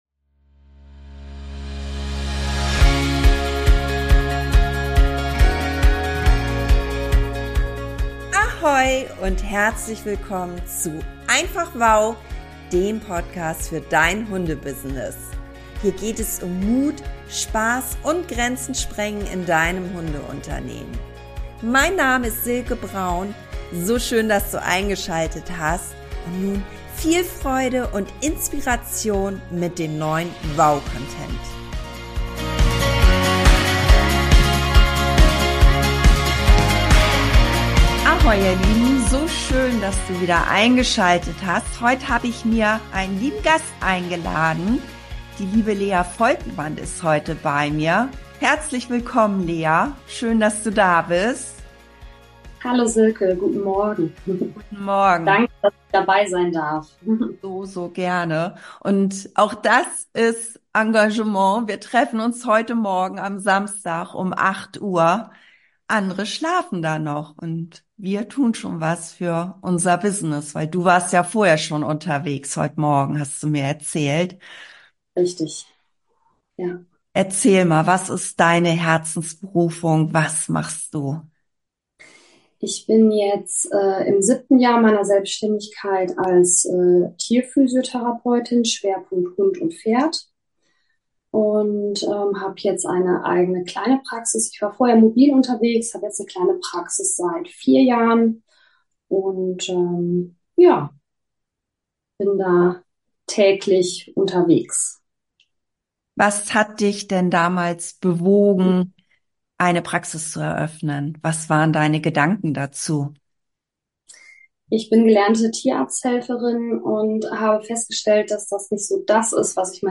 Kundeninterview